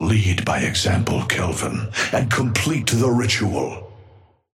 Amber Hand voice line - Lead by example, Kelvin, and complete the ritual.
Patron_male_ally_kelvin_start_03.mp3